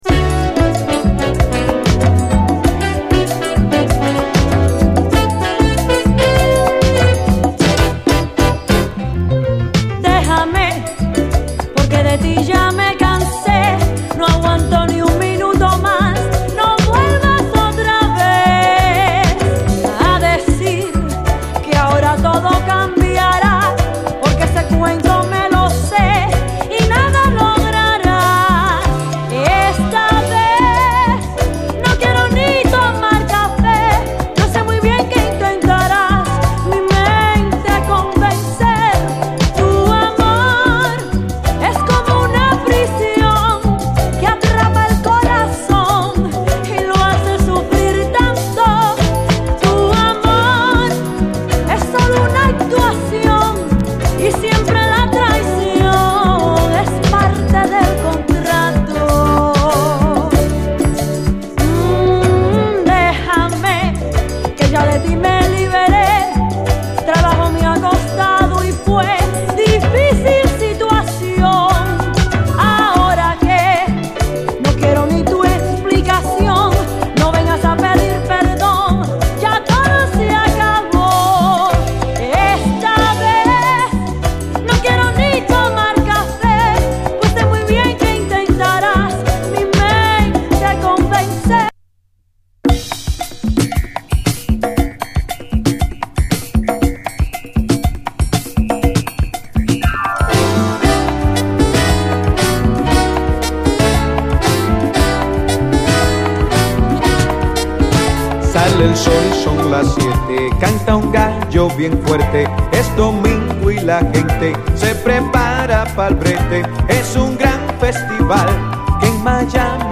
LATIN
軽やかにエレピが弾むメロウ・ラテン・ステッパーや、アーバン・メロウ・グルーヴなど全編最高！